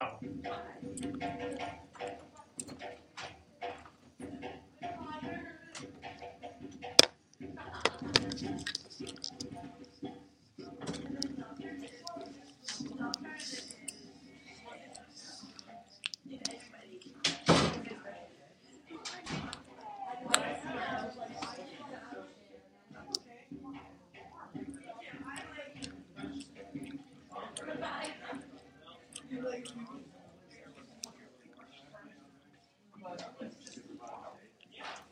Field Recording Two!
Location: Emily Lowe Hall, Hofstra University
Sounds heard: Bongo drums, door opening/shutting, talking, laughing.